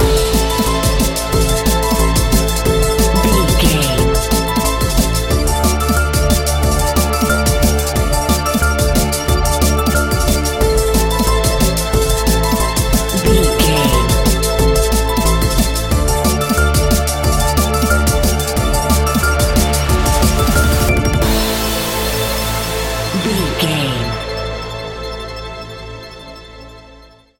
Aeolian/Minor
Fast
aggressive
dark
frantic
synthesiser
drum machine
electric piano
sub bass
synth leads